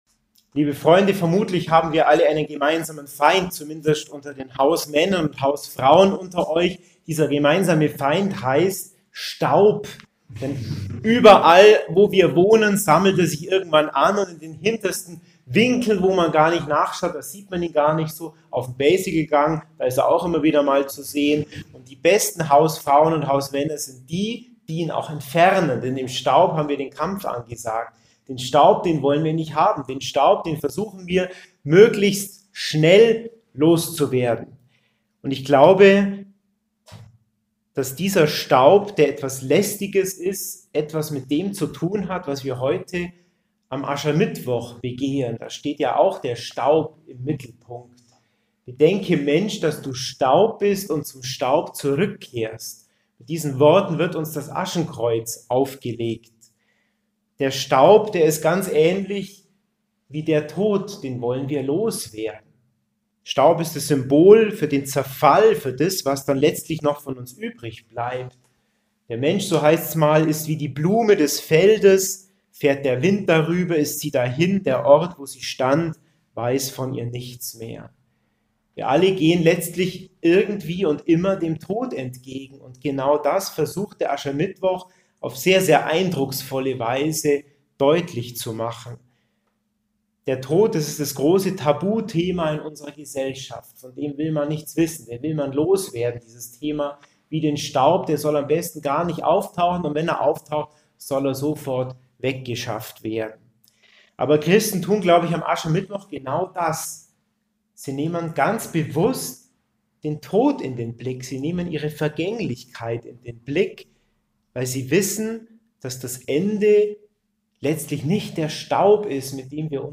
Predigt-Podcast
in der Basical-Kapelle